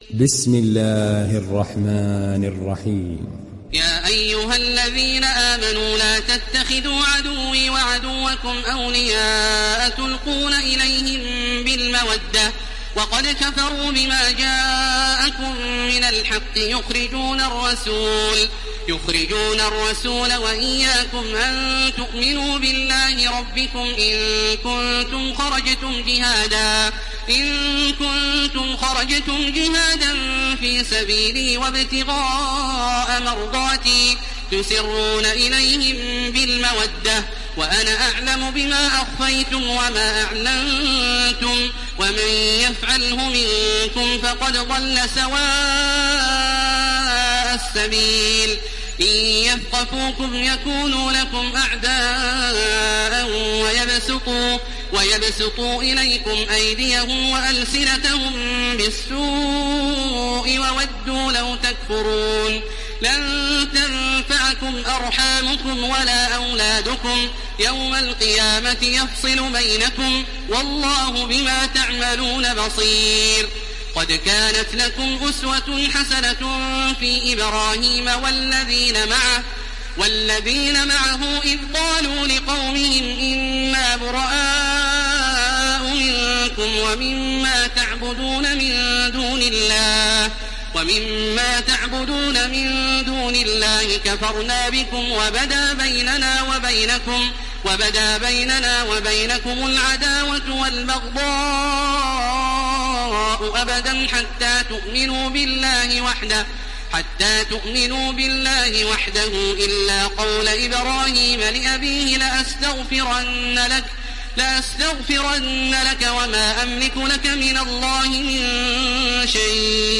دانلود سوره الممتحنه تراويح الحرم المكي 1430